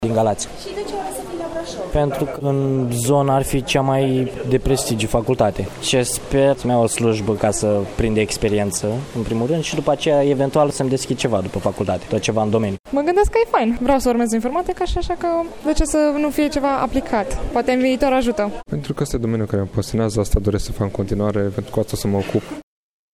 Colegii noştri de la Radio România Braşov FM au stat de vorbă cu câţiva candidaţi: